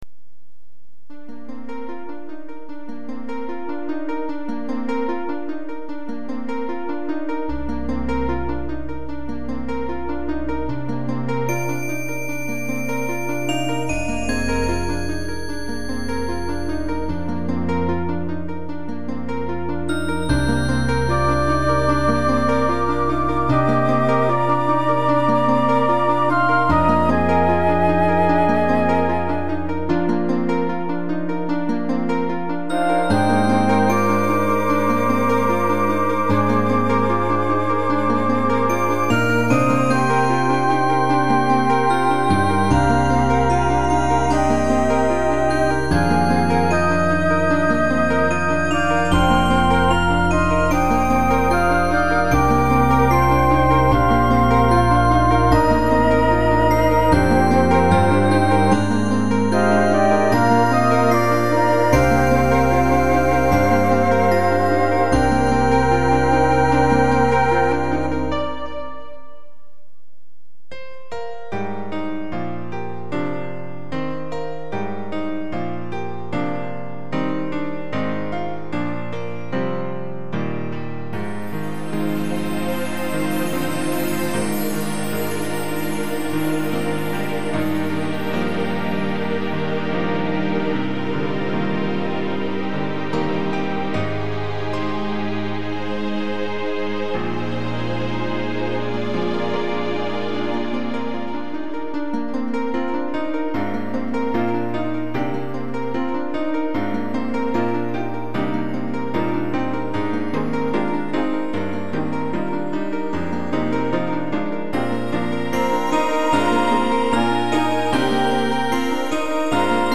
暗さと明るさが共存する。